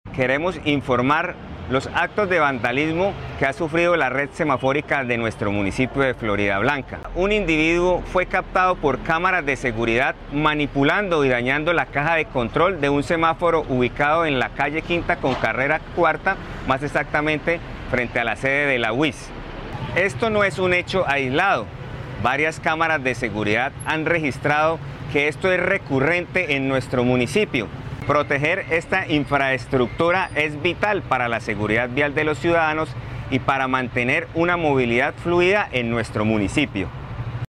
Jahir Castellanos Prada, director de Tránsito de Floridablanca